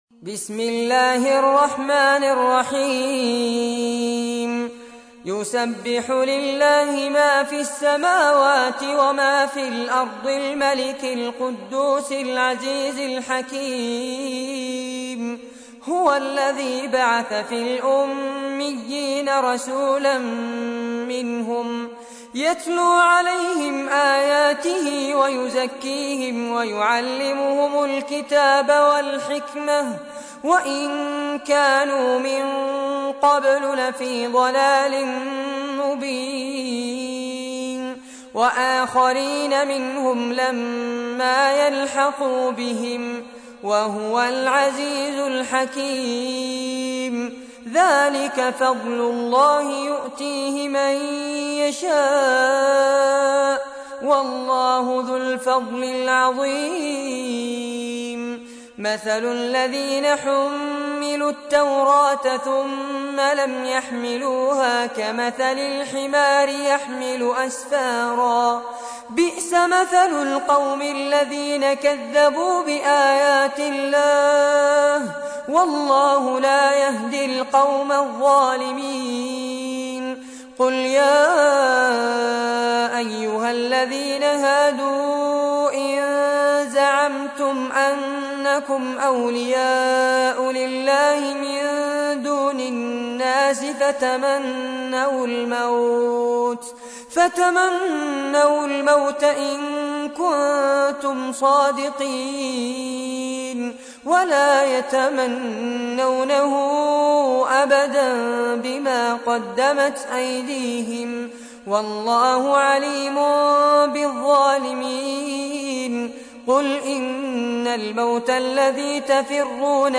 تحميل : 62. سورة الجمعة / القارئ فارس عباد / القرآن الكريم / موقع يا حسين